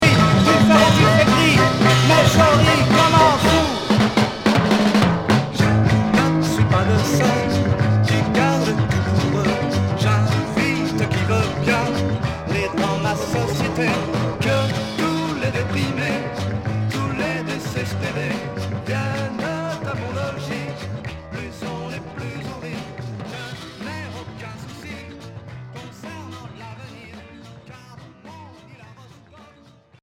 Pop 60's